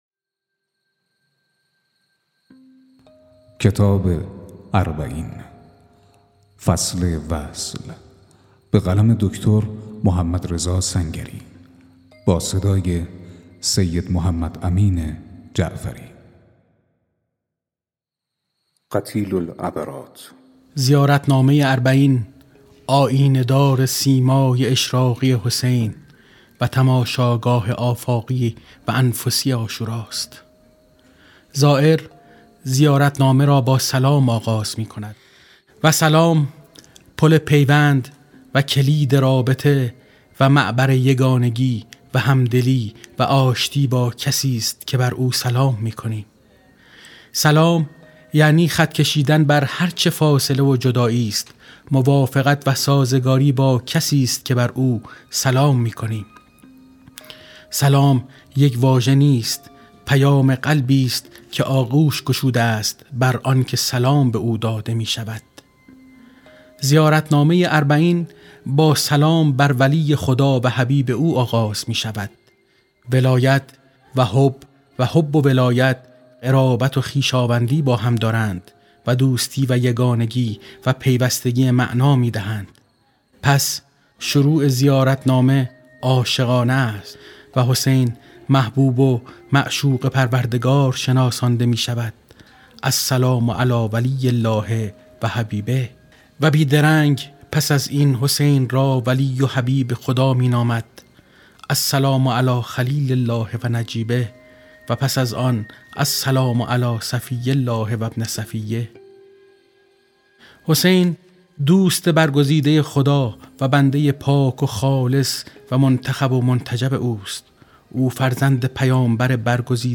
🔻ضبط و آماده‌سازی: استودیو همراز